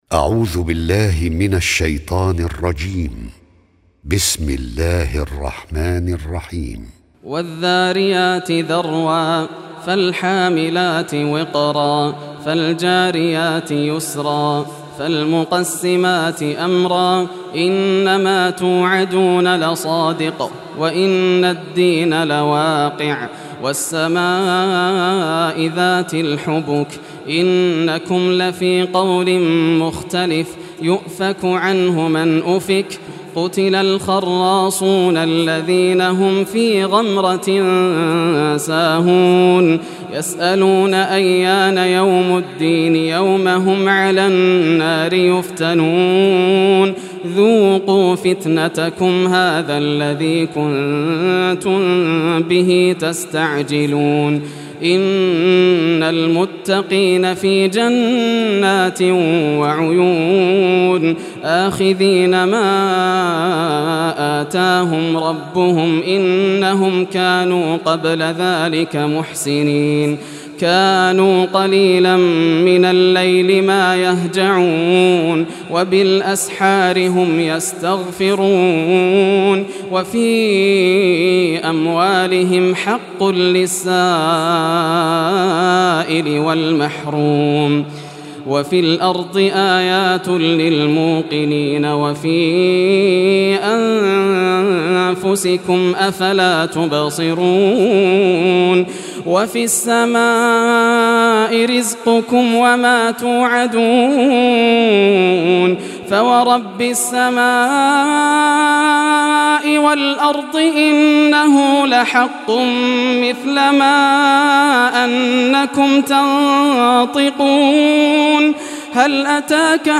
Surah Az-Zariyat Recitation by Yasser al Dosari
Surah Az-Zariyat, listen or play online mp3 tilawat / recitation in Arabic in the beautiful voice of Sheikh Yasser al Dosari.
51-surah-zariyat.mp3